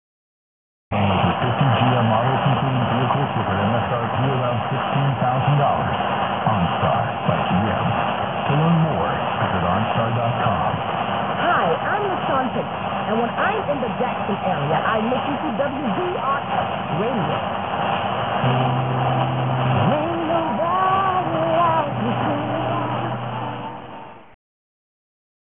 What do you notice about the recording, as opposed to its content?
wow, loud! heavy auroral activity brought this in at 8:45PM.